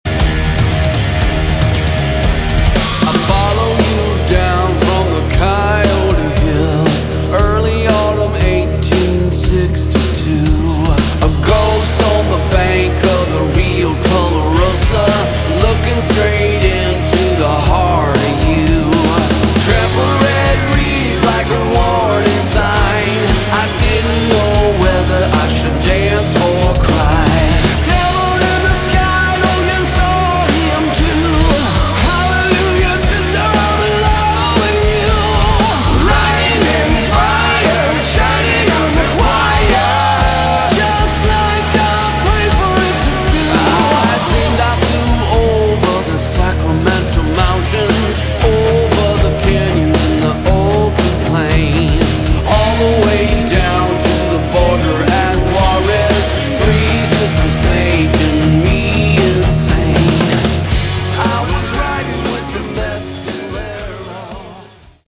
Category: Hard Rock
vocals, guitar
drums